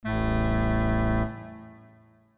klos_bas.mp3